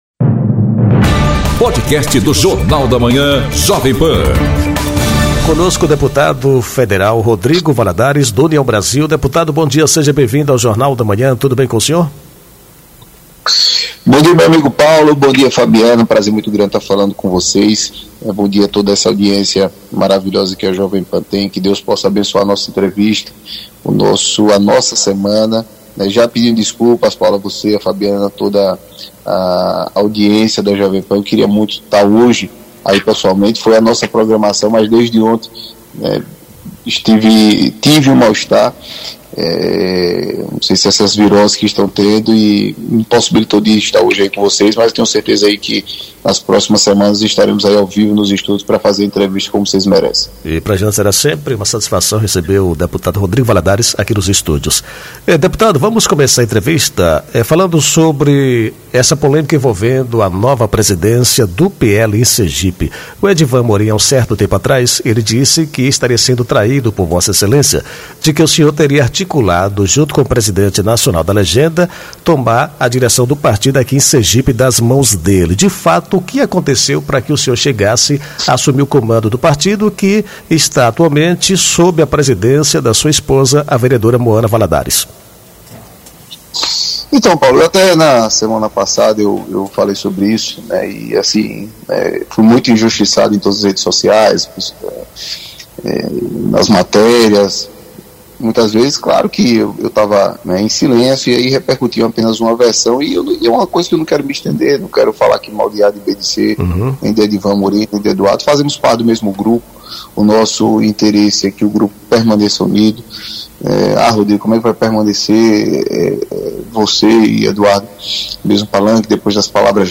Entrevista com o deputado federal Rodrigo Valadares, que avalia a condenação de Bolsonaro e o processo eleitoral do próximo ano, bem como o projeto de anistia.